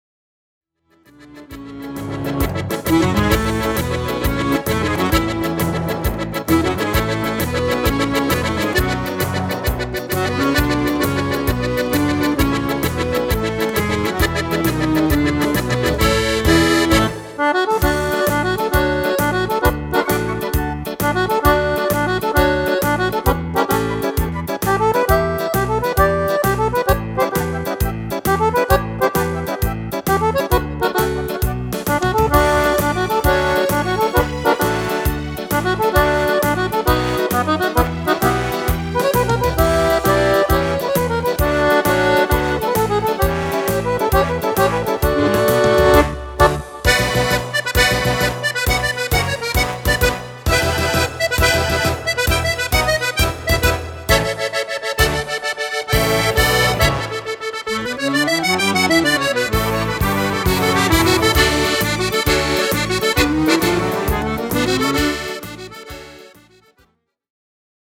Marcia
Fisarmonica
Strumento Fisarmonica (e Orchestra)